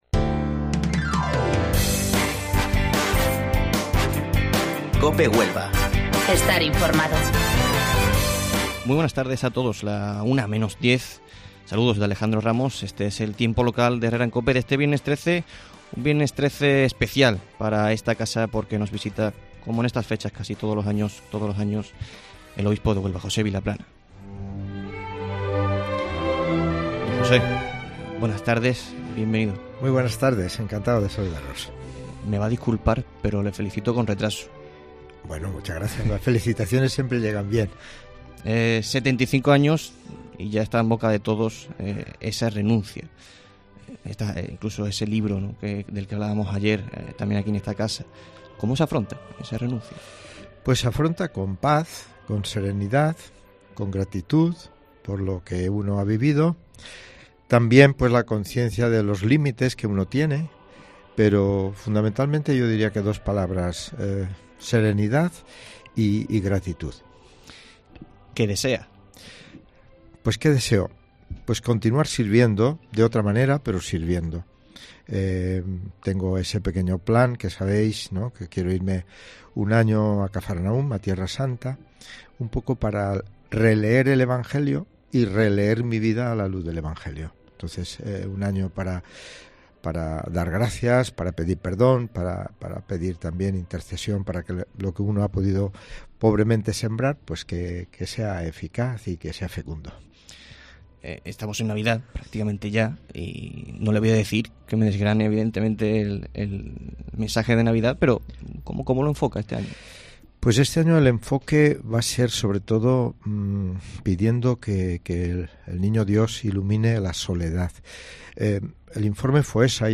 AUDIO: El obispo de Huelva, José Vilaplana, visita COPE Huelva tras su renuncia al papa donde ha analizado el 2019 y sus deseos para el próximo año...